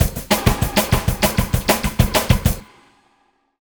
Swinging 60s Drumz Wet.wav